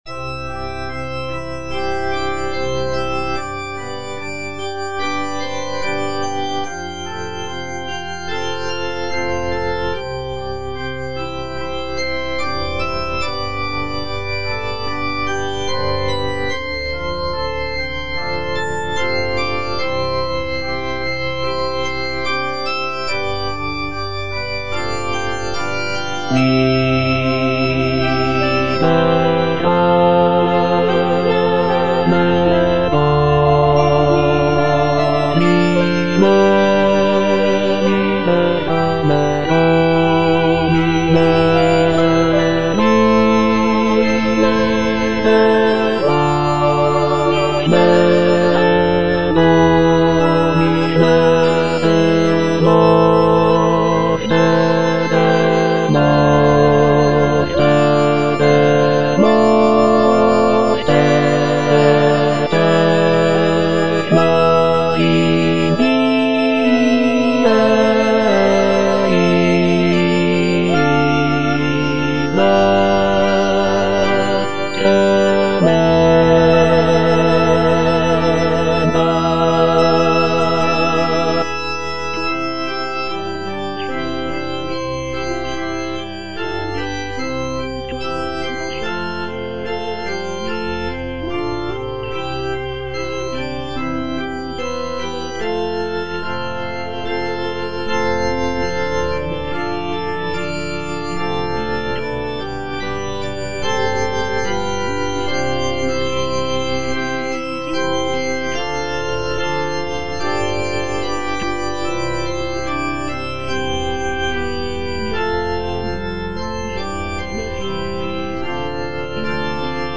Bass (Emphasised voice and other voices) Ads stop
is a sacred choral work rooted in his Christian faith.